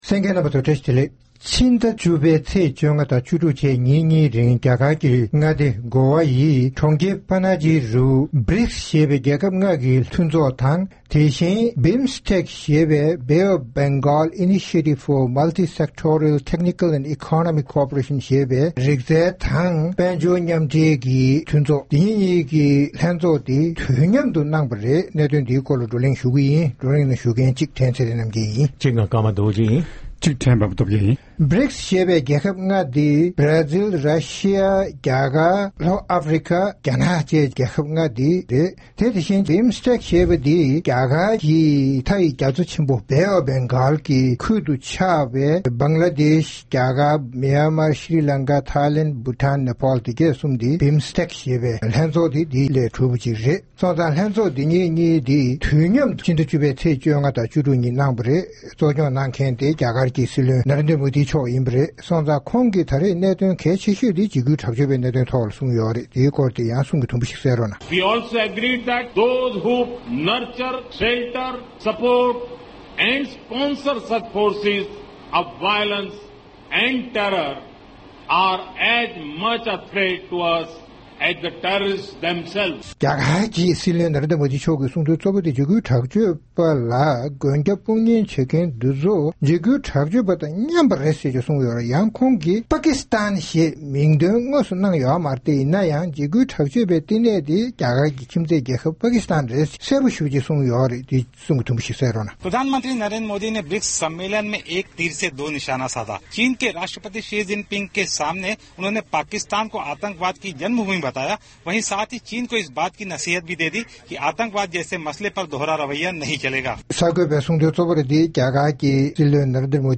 ༄༅། །རྩོམ་སྒྲིག་པའི་གླེང་སྟེགས་ཞེས་པའི་ལེ་ཚན་ནང་། BRICKS ཞེས་པའི་རྒྱལ་ཁབ་ལྔ་དང་། BIMSTEC ཞེས་པའི་རྒྱ་མཚོ་ཆེན་པོ་བེ་ཨོཕ་བྷང་གྷལ་Bay of Bengal གྱི་ཁུལ་དུ་ཆགས་པའི་རྒྱལ་ཁབ་བདུན་གྱི་ལྷན་ཚོགས་རྒྱ་གར་གྱི་ནུབ་བྱང་མངའ་སྡེ་Goa ཡི་གྲོང་ཁྱེར་Panaji རུ་ཕྱི་ཟླ་༡༠་པའི་ཚེས་༡༥་དང་༡༦་བཅས་ཉིན་གཉིས་རིང་དུས་མཉམ་དུ་ཚོགས་གནང་པའི་དོན་འབྲས་སོགས་སྐོར་རྩོམ་སྒྲིག་འགན་འཛིན་རྣམ་པས་བགྲོ་གླེང་གནང་བ་ཞིག་གསན་རོགས་གནང་།